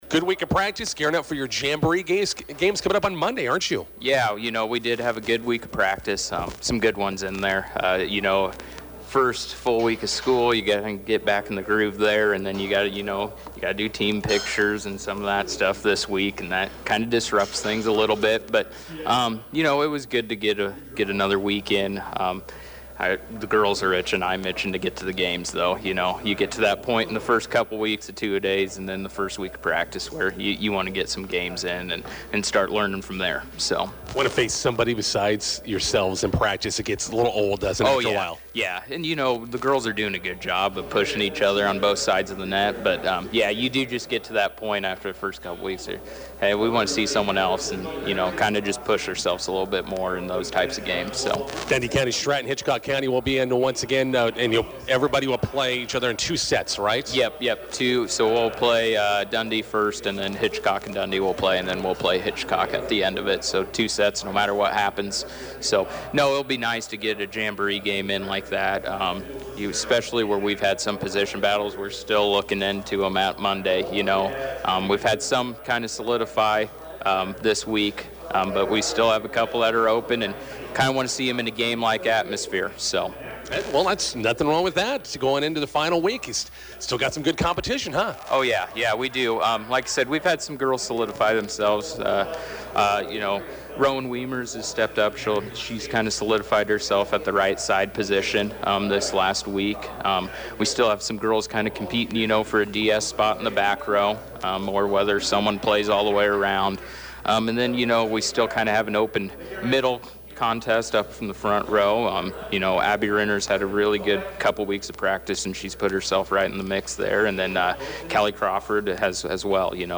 INTERVIEW: Bison volleyball hosts Dundy County-Stratton/Hitchcock County in NSAA Jamboree matches tonight.